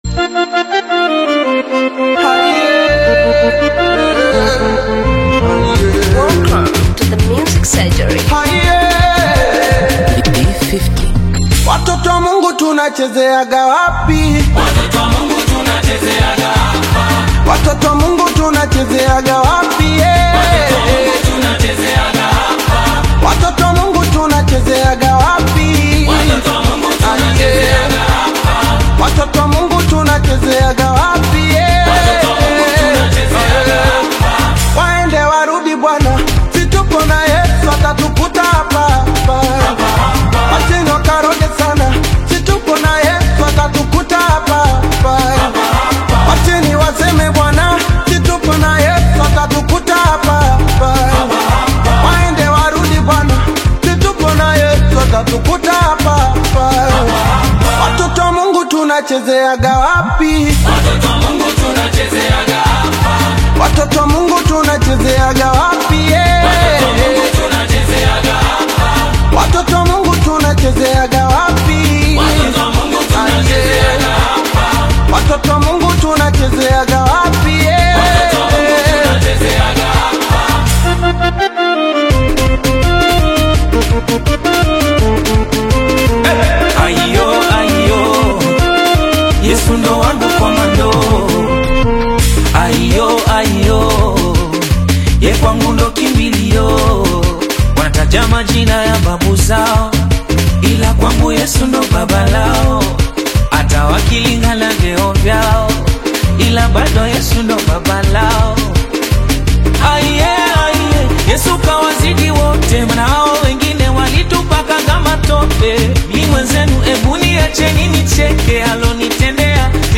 AudioGospel
Genre: Gospel